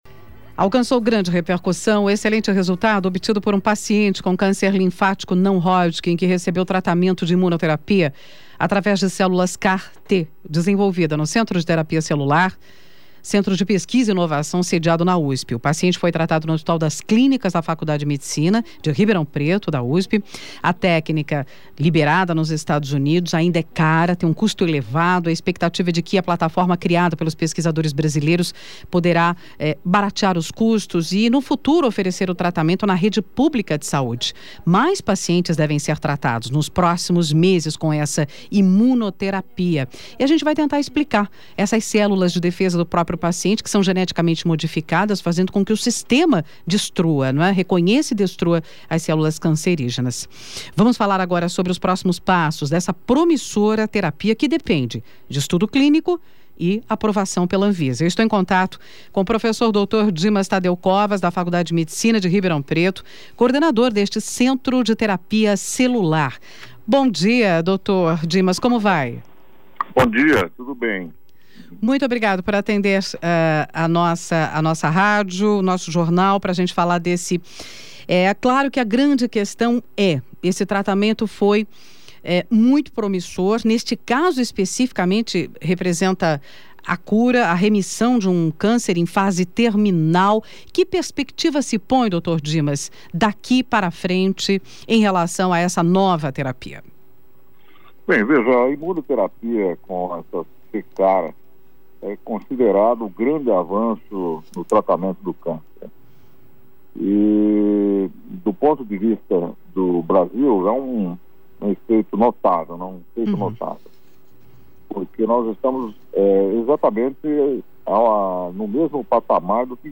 O Jornal da USP no Ar conversa sobre a iniciativa com o professor da FMRP, Dimas Tadeu Covas, coordenador do Centro de Terapia Celular e do Instituto Nacional de Células-Tronco e Terapia Celular.